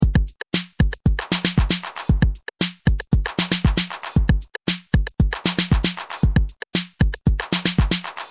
Tre trummaskiner, dom rætta svaren!
Alla ljud är samlingar och sequensade i Tunafish.
A låter som trum-ljuden från ett gammat soundblaster-kort.
Att kalla 10 sek klipp med enbart trummor för "låtar" och "extremsunk" känns som en kraftig överdrift.